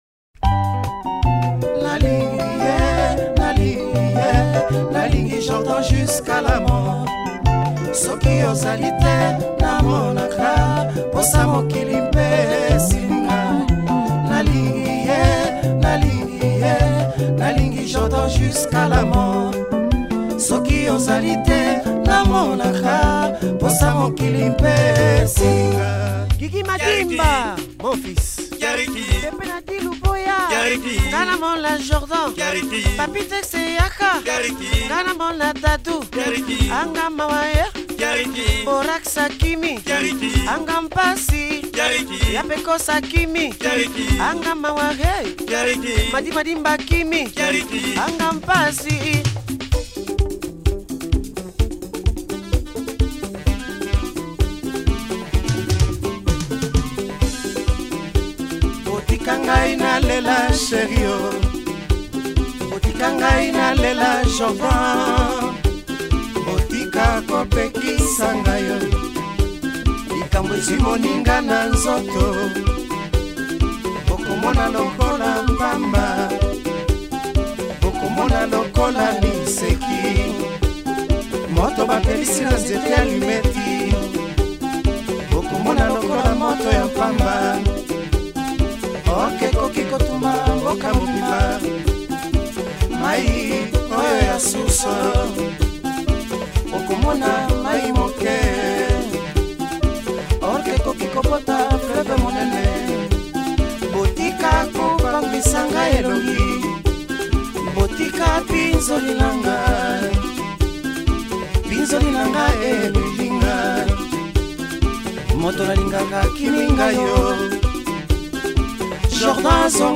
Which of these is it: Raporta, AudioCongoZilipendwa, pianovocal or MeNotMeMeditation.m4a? AudioCongoZilipendwa